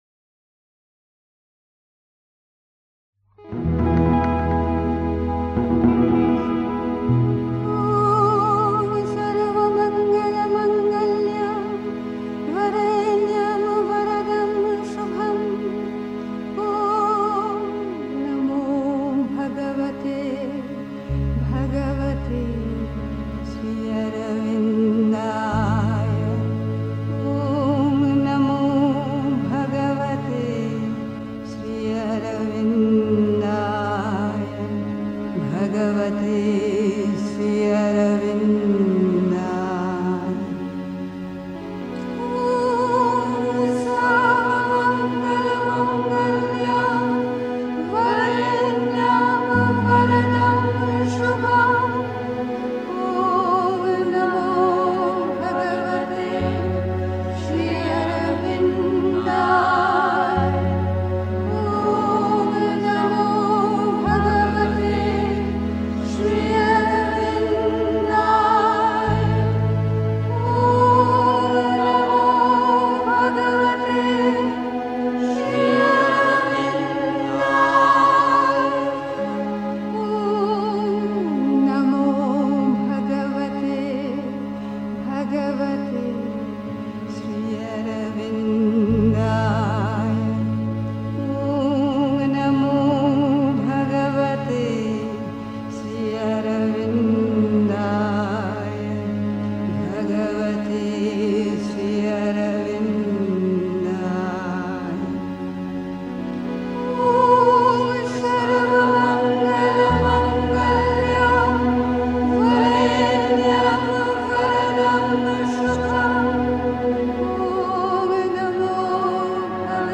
Der Grund, warum die Welt sich in einem so schmerzhaften Chaos befindet (Die Mutter, White Roses, 30 August 1963) 3. Zwölf Minuten Stille.